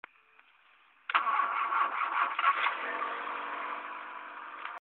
Zapalanie.mp3